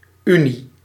Ääntäminen
Synonyymit conjugaison mariage Ääntäminen France: IPA: [y.njɔ̃] Haettu sana löytyi näillä lähdekielillä: ranska Käännös Ääninäyte Substantiivit 1. unie {f} 2. eendracht {f} 3. eenheid {n} 4. samenvoeging Suku: f .